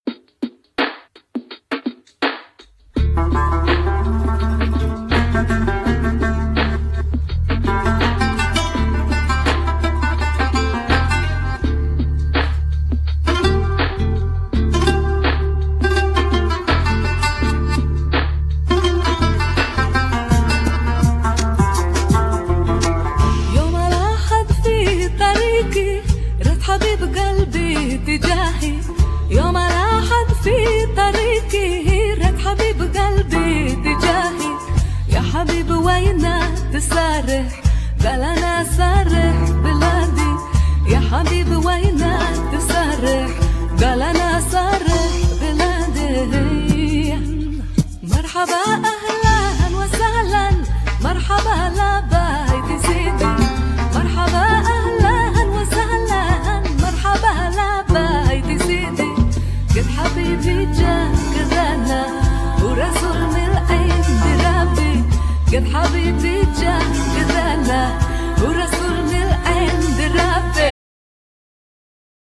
Genere : Pop Etno